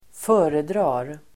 Uttal: [²f'ö:redra:r]